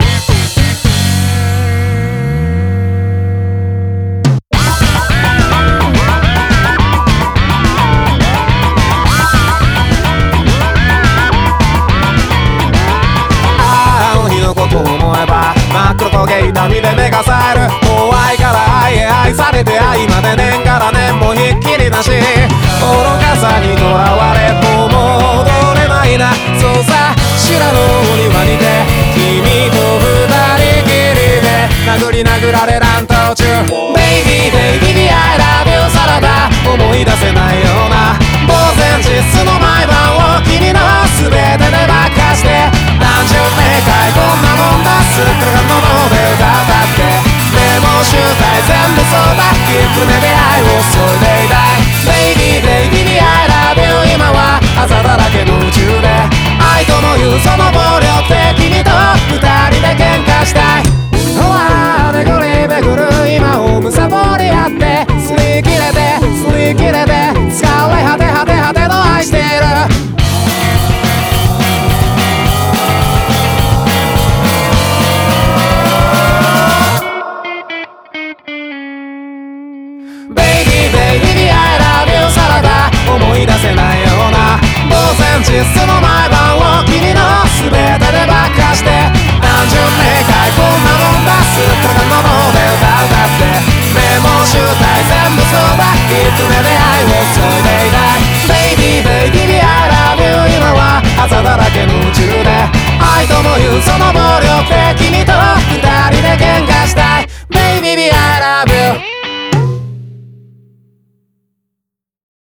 BPM212